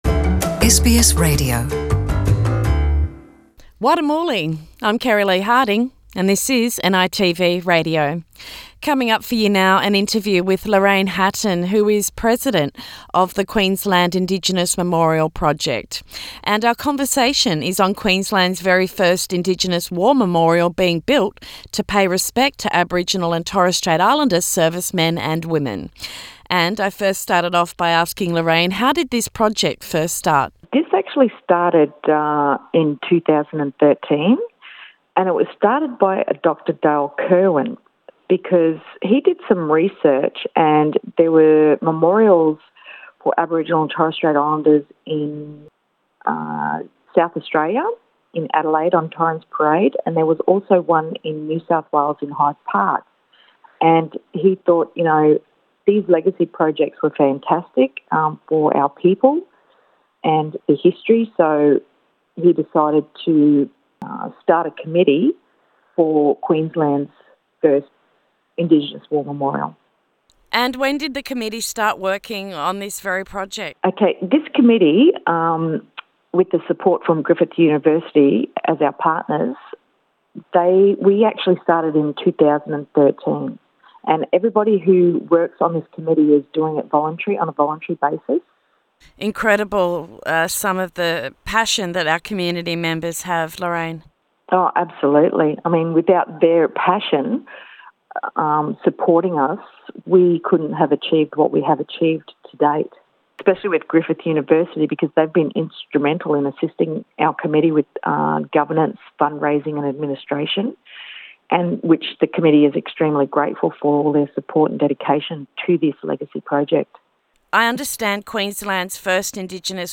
In conversation